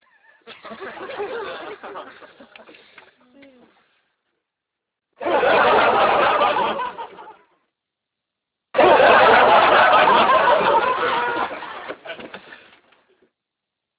Studio Audience.amr